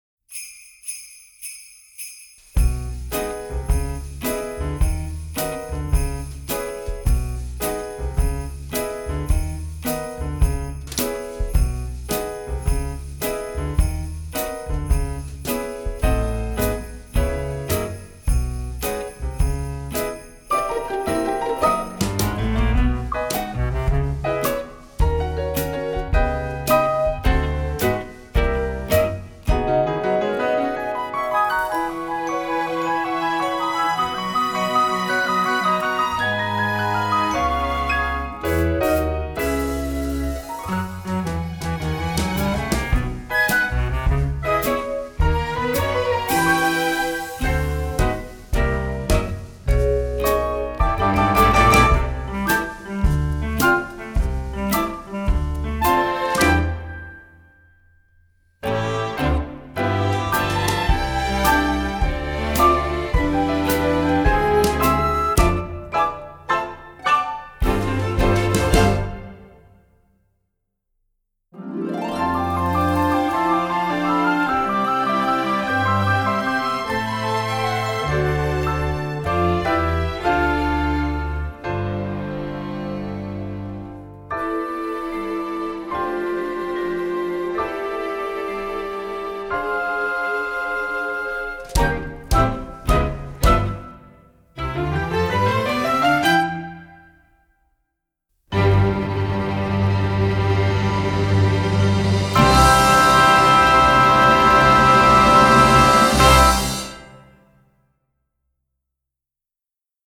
Song with lyrics